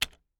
click-short-confirm.mp3